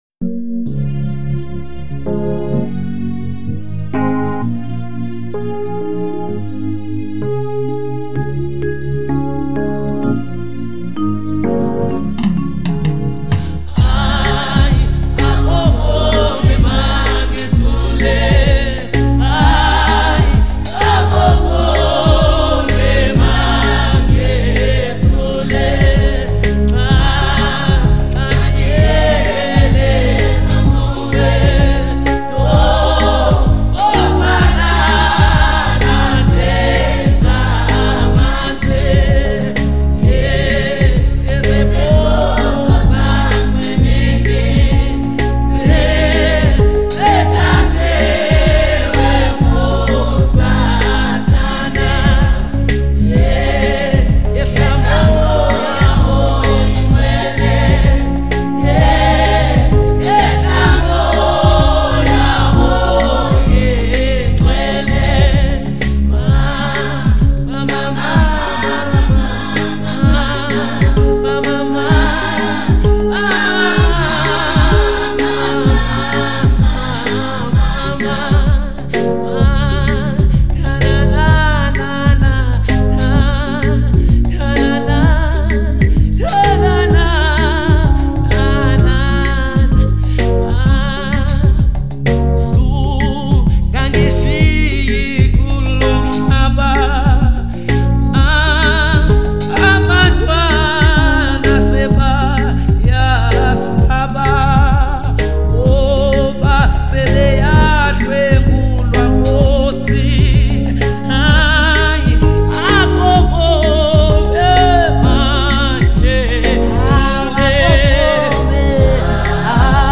GENRE: South African Gospel.